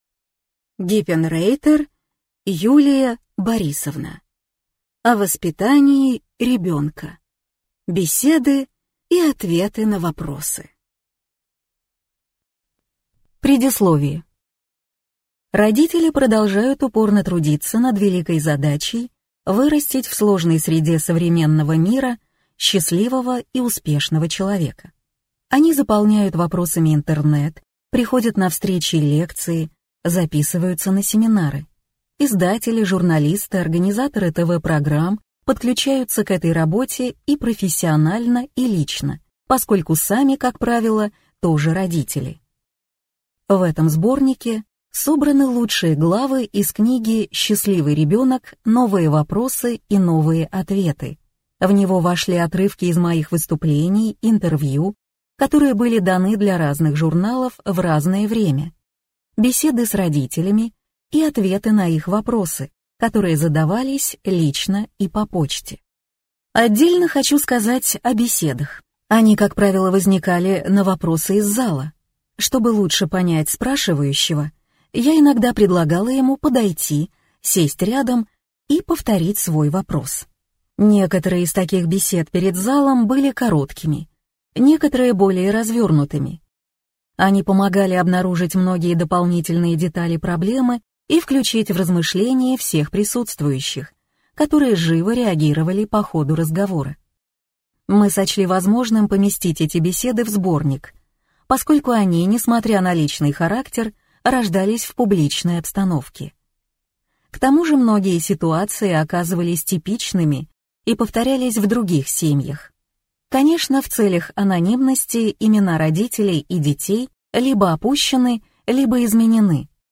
Аудиокнига О воспитании ребенка: беседы и ответы на вопросы | Библиотека аудиокниг